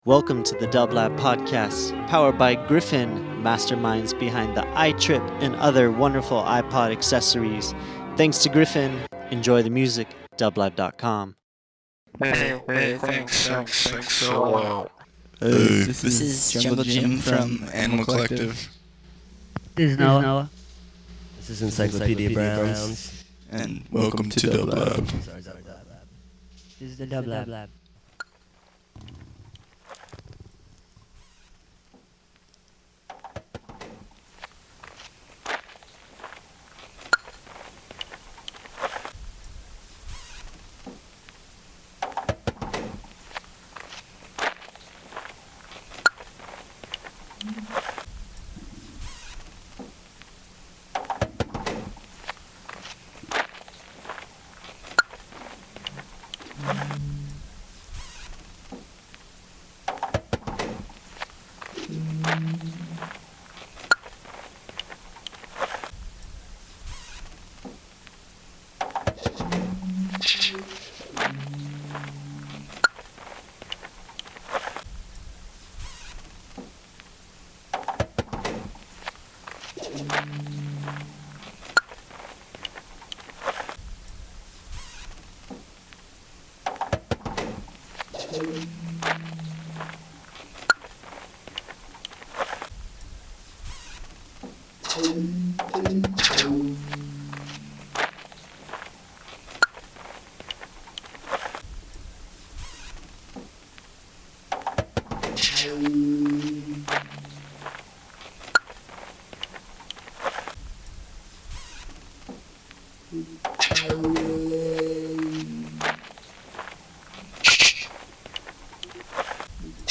Avant-Garde Electronic Folk